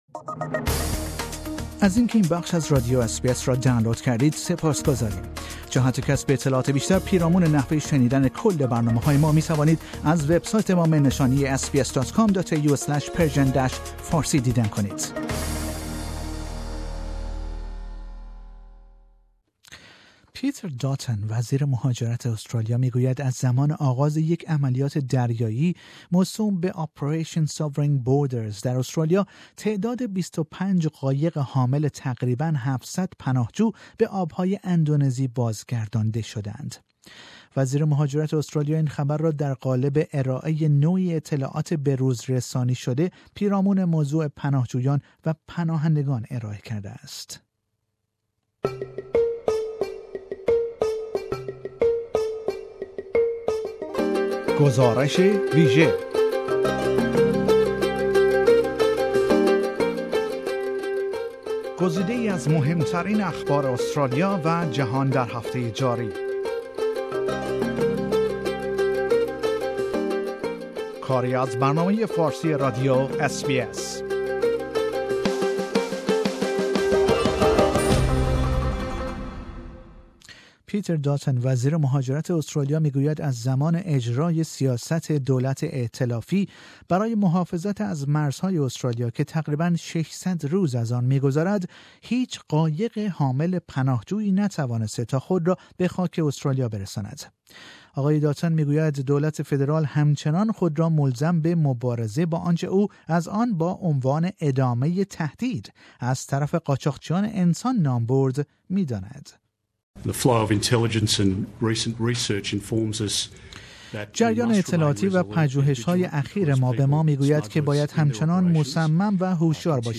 گزارش مطبوعاتی وزیر مهاجرت استرالیا درباره سیاست بازگرداندن قایق های حامل پناهجویان